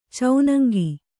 ♪ caunaŋgi